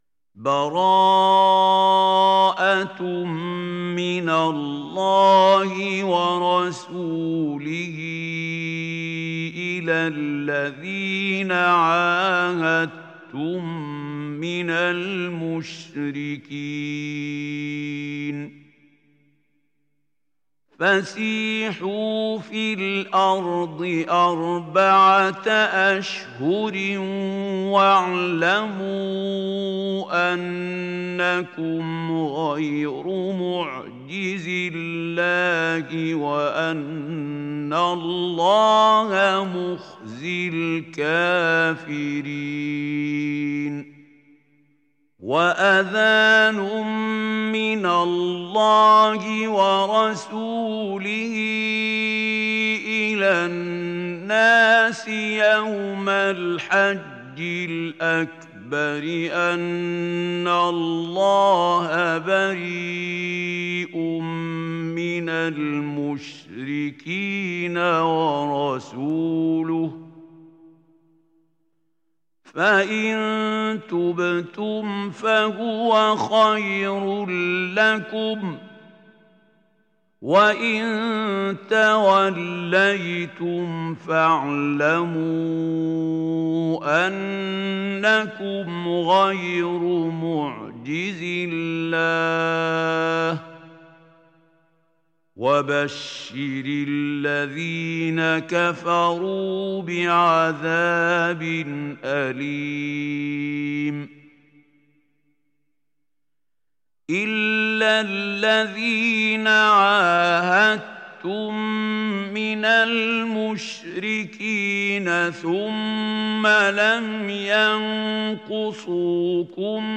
تحميل سورة التوبة mp3 بصوت محمود خليل الحصري برواية حفص عن عاصم, تحميل استماع القرآن الكريم على الجوال mp3 كاملا بروابط مباشرة وسريعة